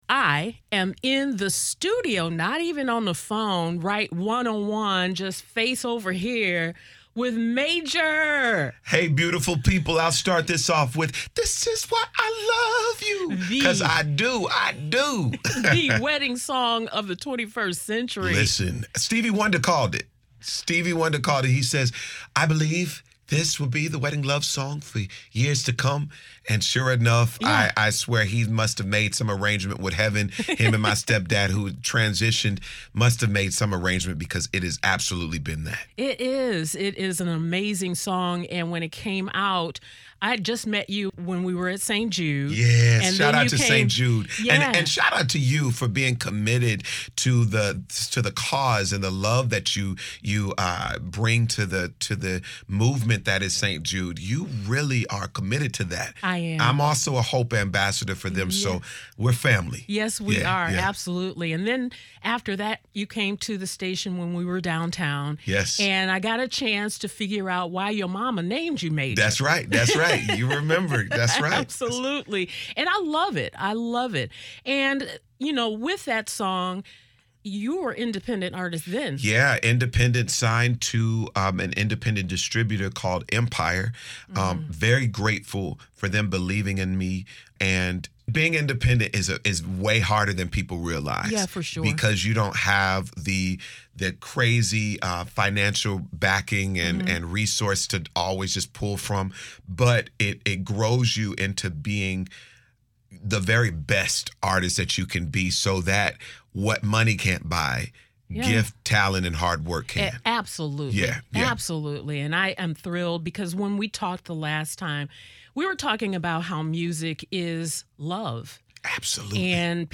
What a pleasure to have Grammy, Soul Train and NAACP Image Award nominated MAJOR. in the Praise studio with me today.MAJOR’s “This is Why I Love You” recently reached platinum status but with his new single… he returns to his inspirational roots… not that he ever left.